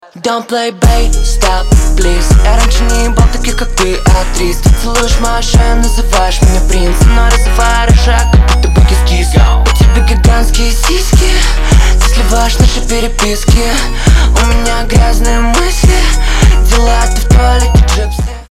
• Качество: 320, Stereo
мужской голос
Пошлый рэпчик